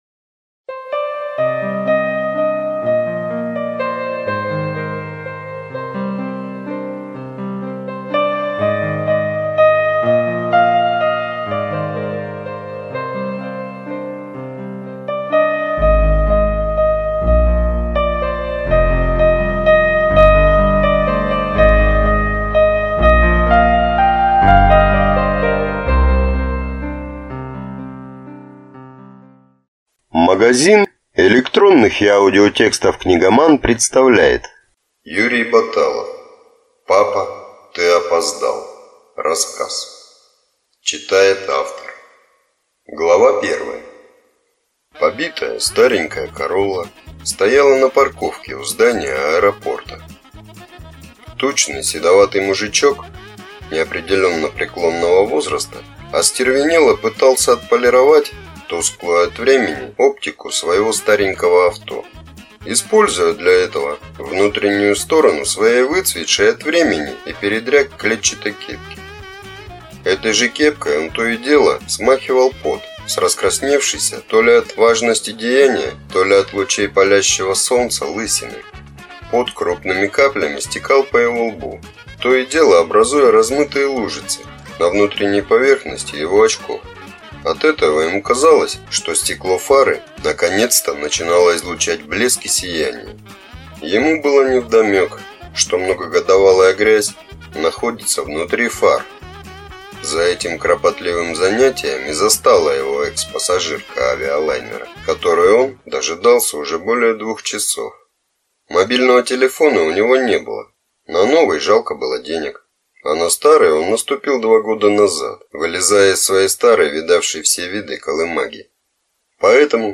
Аудиокнига Сборник рассказов